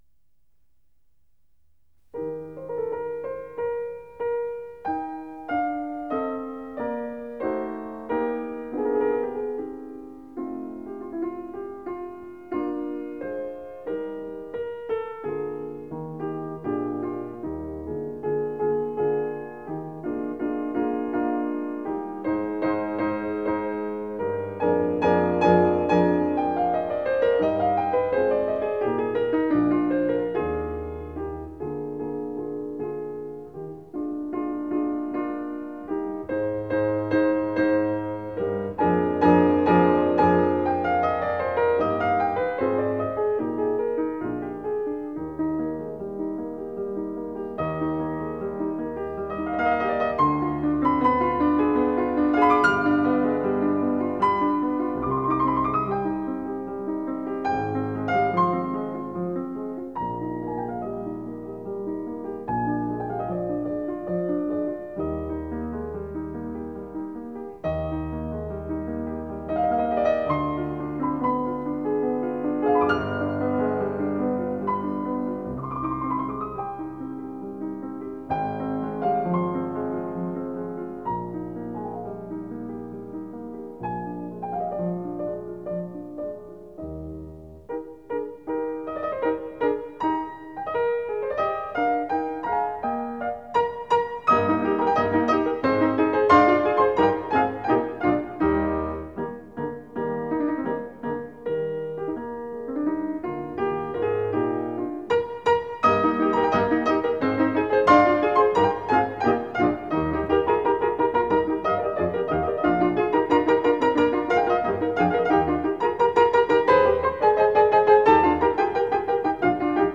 Piano Works/Piano Duets